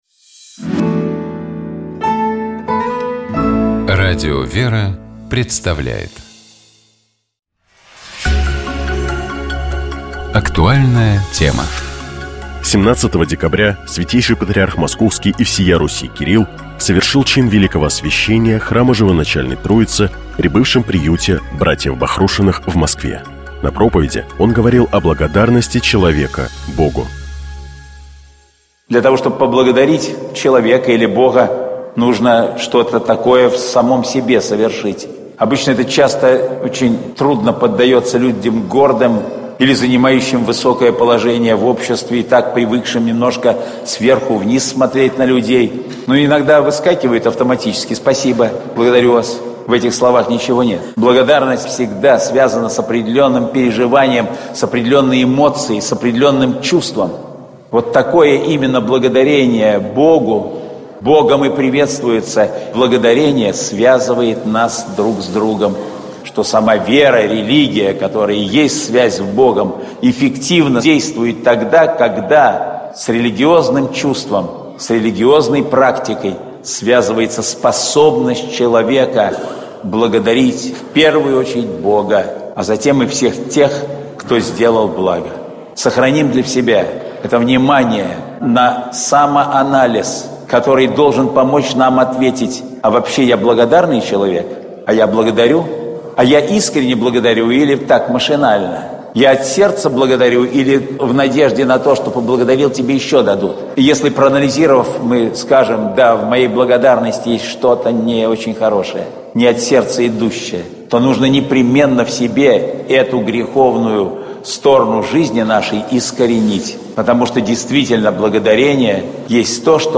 17 декабря Святейший Патриарх Московский и всея Руси Кирилл совершил чин великого освящения храма Живоначальной Троицы при бывшем приюте братьев Бахрушиных в Москве.
На проповеди он говорил о благодарности человека Богу: